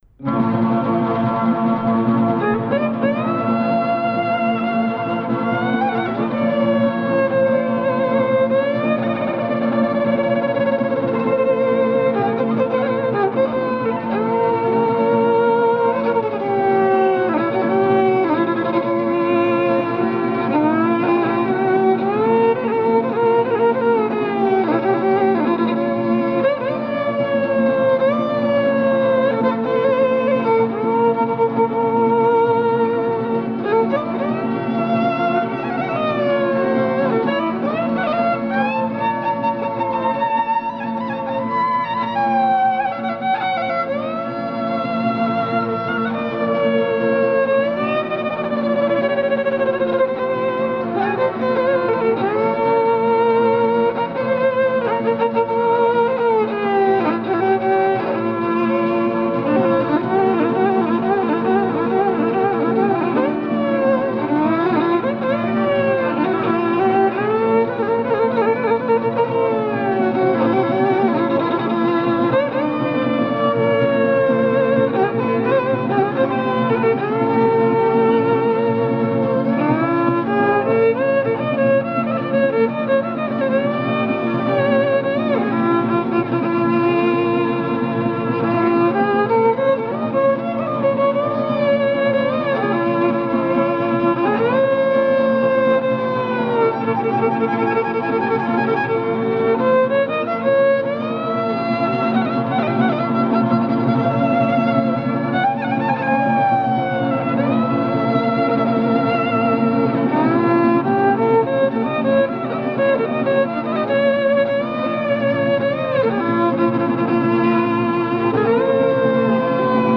Kaba me dy pjesë: kaba dhe valle. Pjesa e parë shfaqet si kaba baritorore mbi motive instrumentale të veglave tradicionale të Toskërisë por edhe të përcjelljeve instrumentale të valleve dyshe të Myzeqesë toske. Në këtë pjesë ndjehet mjaft edhe ndikimi i lojës së kabave me gërnetë sidomos në një nga motivet që duket se rrjedh nga tradita muzikore vajtimore labe. Pjesa e dytë, valle vjen menjëherë pas motivit lab dhe duket se e ҫliron tensionin që krijohet në pjesën e parë nga vajtimi.